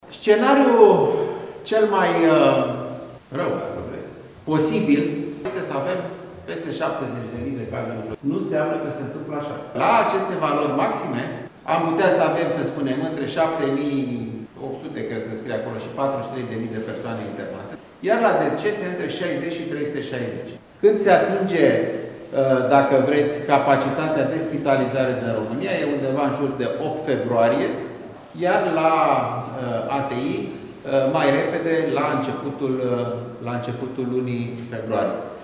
„Scenariul cel mai rău posibil este să avem peste 70.000 de cazuri noi, nu înseamnă că se întâmplă așa. (…) Când se atinge capacitatea de spitalizare în România, este în jur de 8 februarie, iar la ATI mai repede, la începutul lunii februarie”, a spus Alexandru Rafila, ministrul Sănătății, la conferința de prezentare a estimărilor evoluției Covid-19.